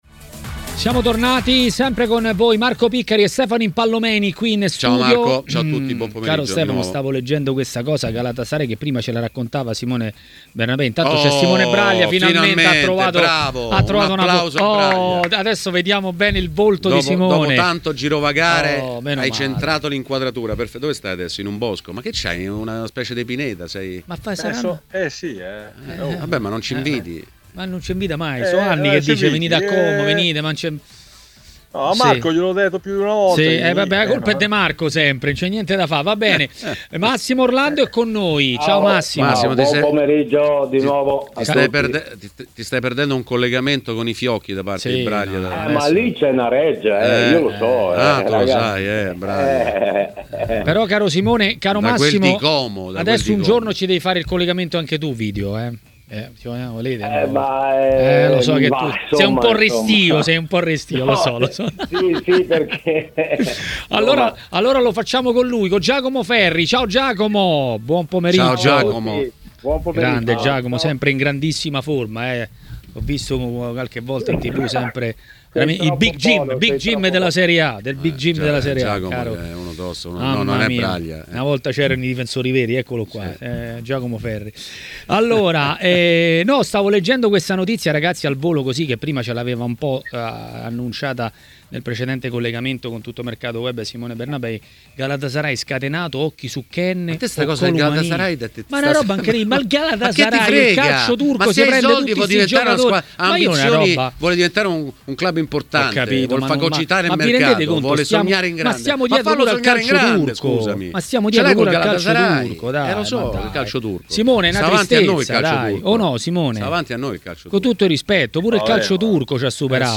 è stato intervistato a Maracanà, nel pomeriggio di TMW Radio.